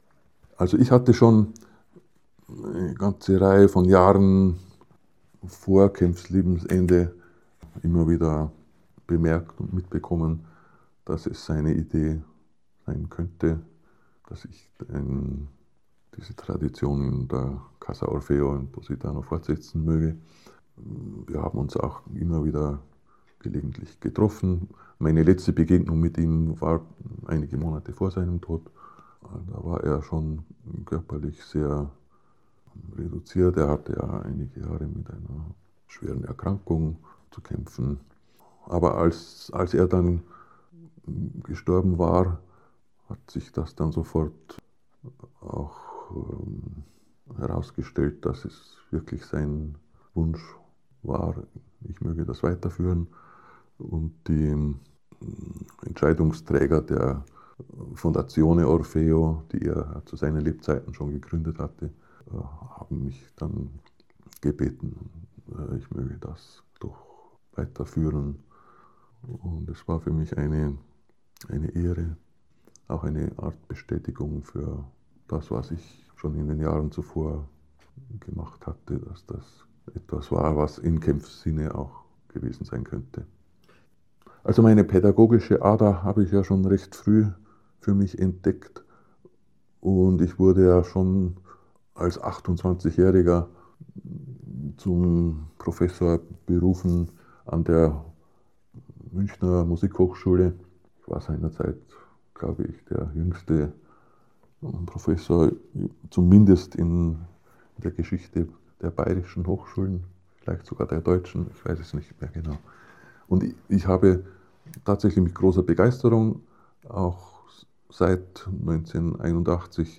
In eight chapters, he reports on the masterclasses at Casa Orfeo and his encounters with Wilhelm Kempff.